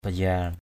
/ba-za:r/